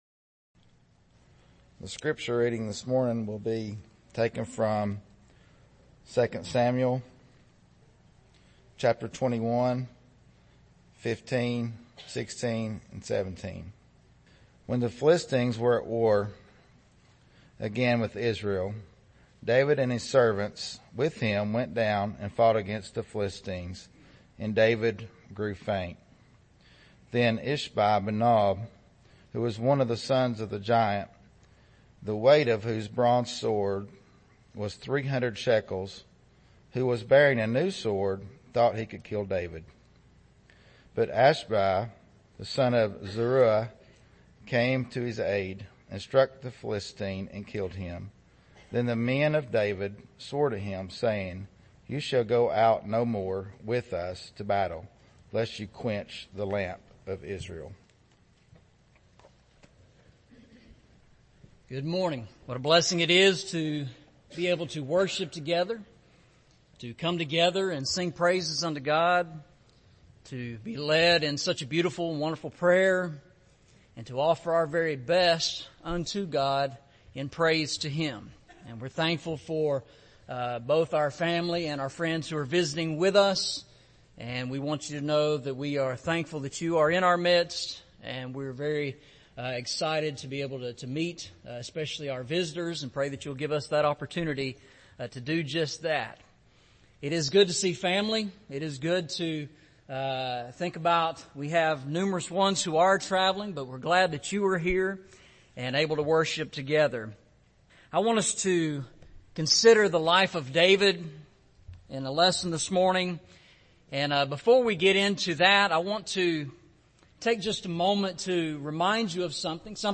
Eastside Sermons Service Type: Sunday Morning Preacher